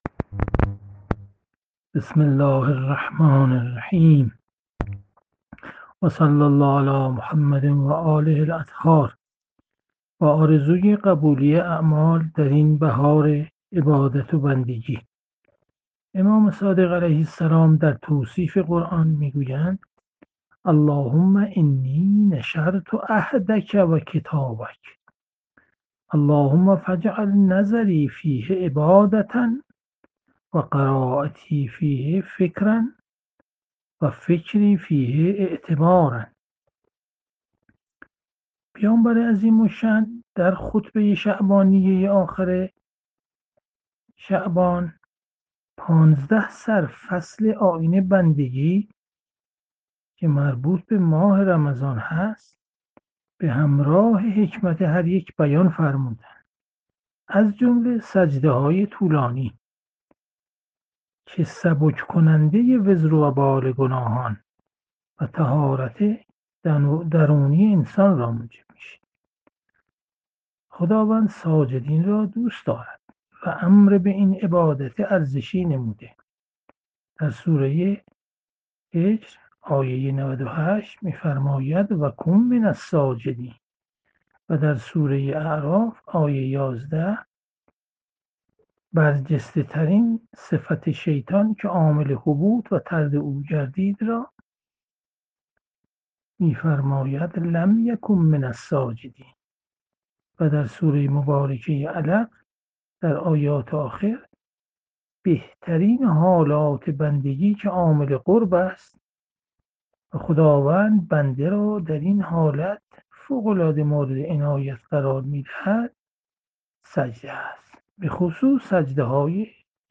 جلسه مجازی هفتگی قرآنی، سوره فجر، 14 فروردین 1401
• تفسیر قرآن